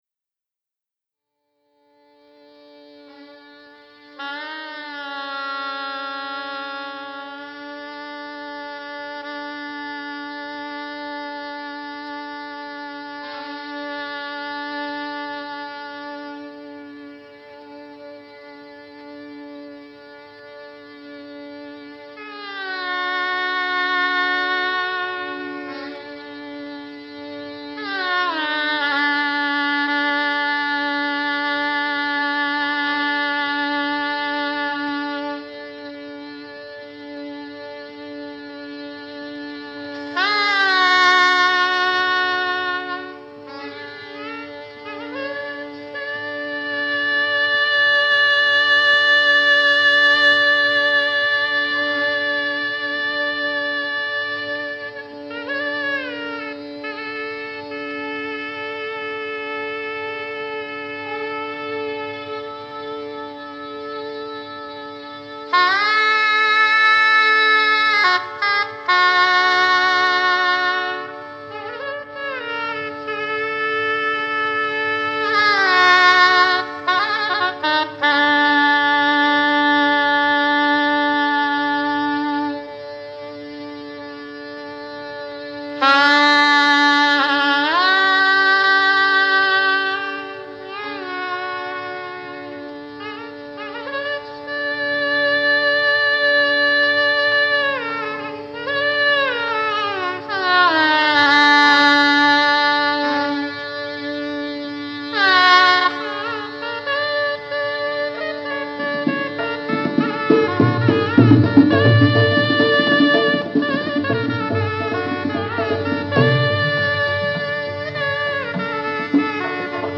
బసంత్ రాగ్ – బిస్మిల్లా ఖాన్ షహనాయీ బహార్ రాగ్ – రవిశంకర్ సితార్